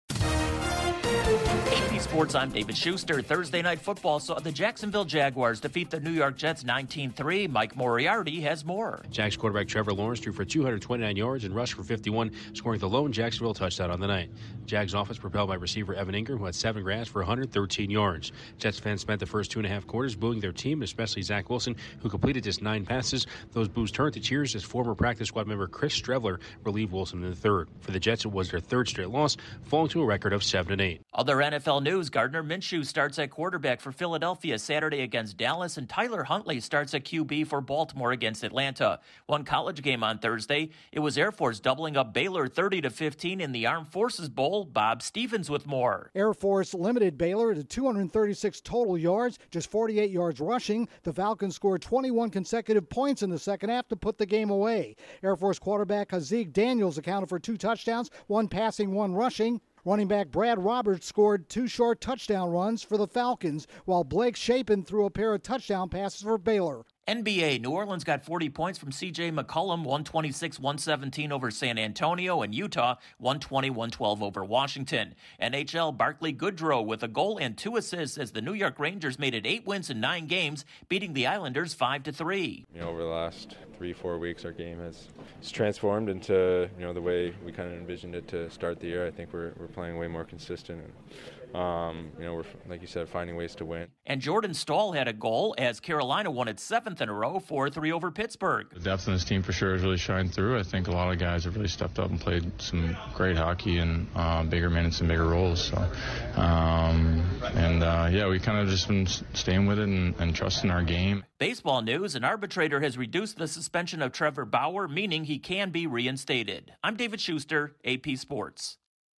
Another drab Thursday night NFL game as well as a not so scintillating college football bowl game; a big scoring night for one player on a short two game NBA schedule; a couple of NHL teams stay hot; a controversial player might be reinstated to Major League Baseball. Correspondent